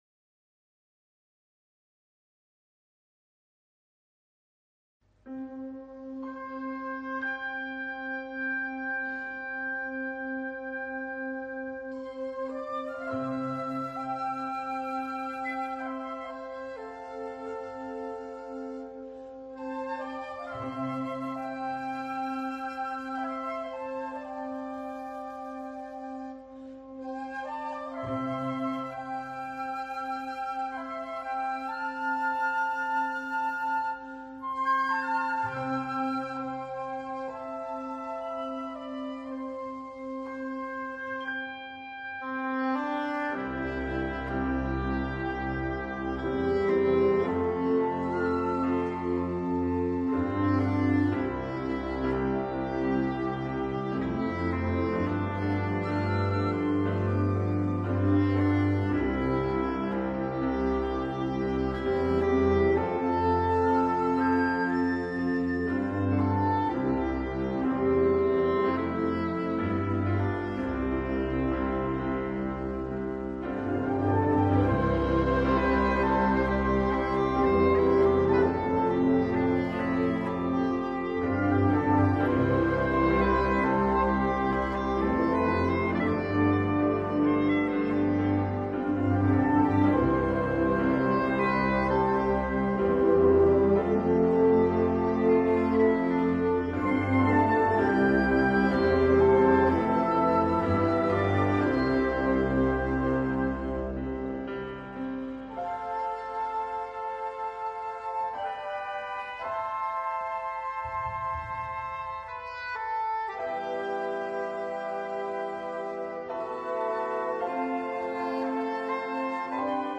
Gattung: für Solo - Englischhorn und Blasorchester
Besetzung: Blasorchester
Wunderschönes Werk  für Solo - Englischhorn und Orchester